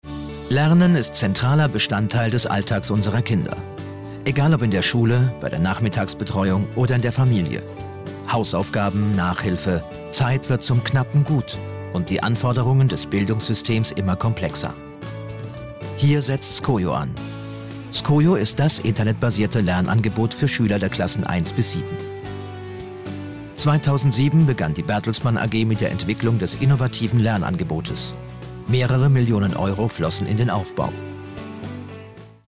sehr variabel
Mittel plus (35-65)
Commercial (Werbung)